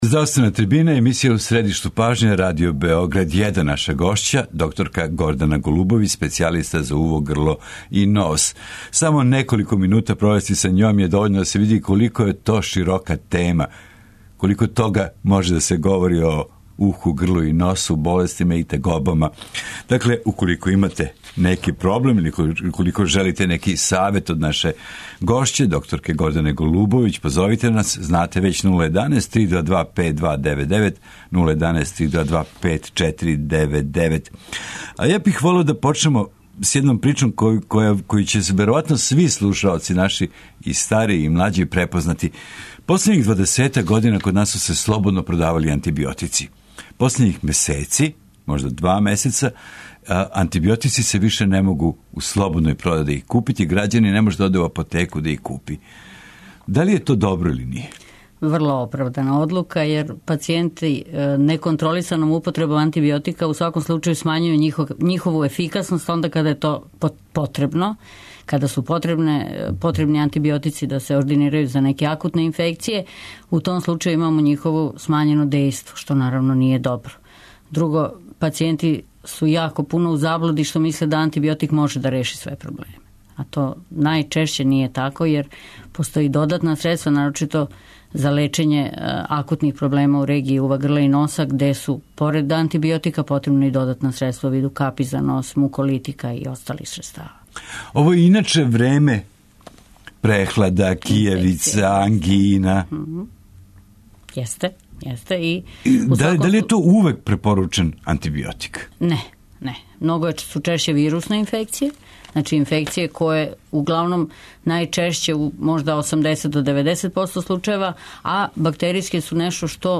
У здравственој трибини емисије У средишту пажње говоримо о болестима уха, грла и носа. Зимски дани су дани када су релативно честе упале грла, крајника, средњег уха, синуса, кашља који траје данима...